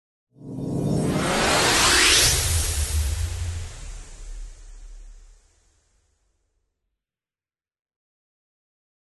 Быстрый звук перемещения человека в прошлое для монтажа